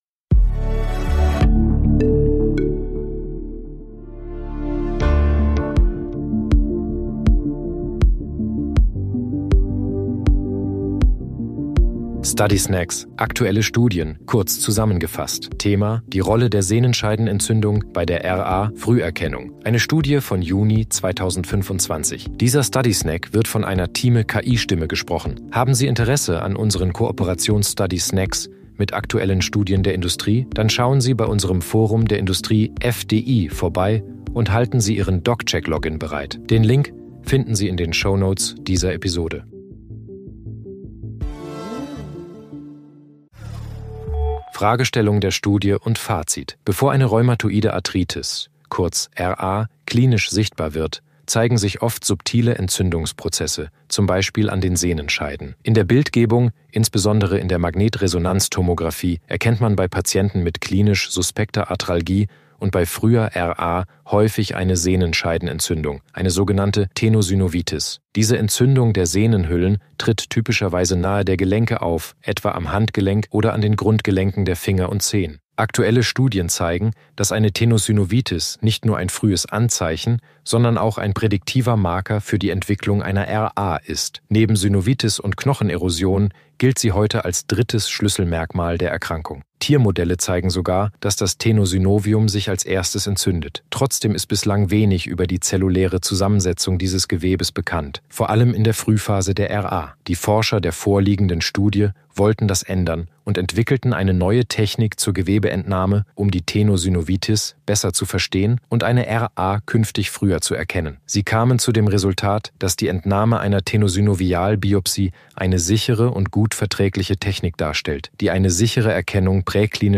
sind mit Hilfe von künstlicher Intelligenz (KI) oder maschineller